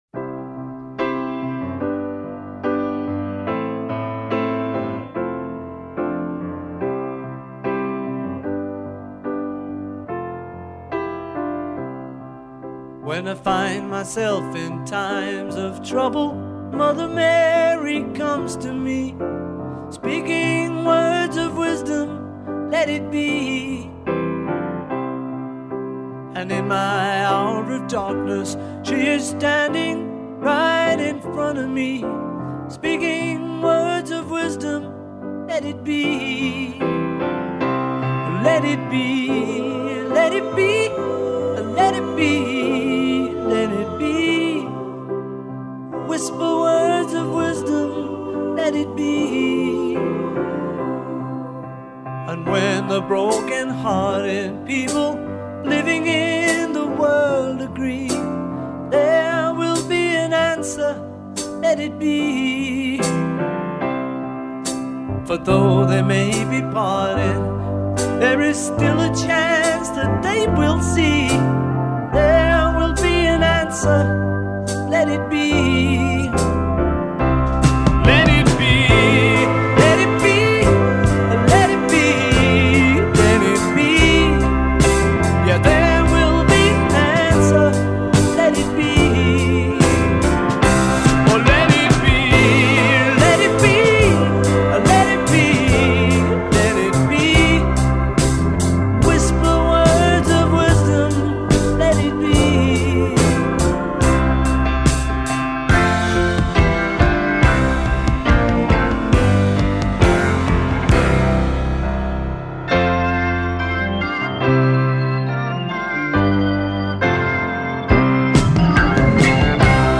西洋音樂
全新高科技數位還原